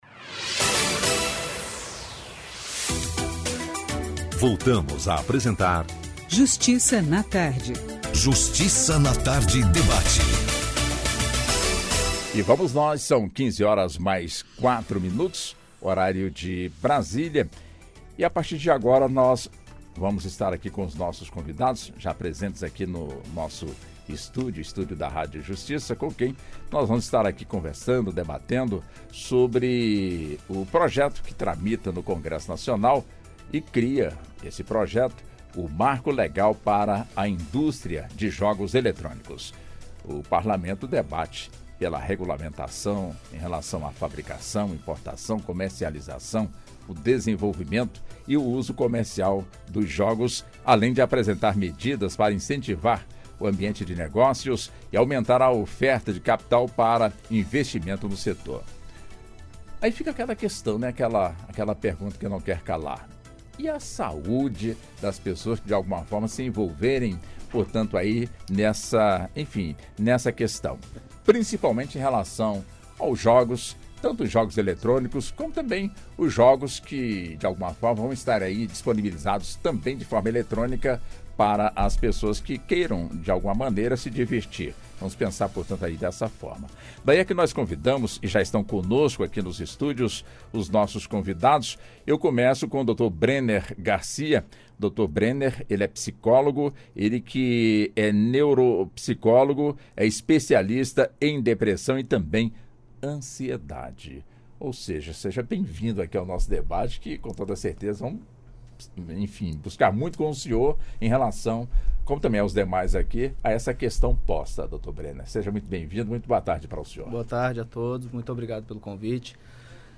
ENTREVISTA | Rádio Justiça | Marco Regulatório dos jogos eletrônicos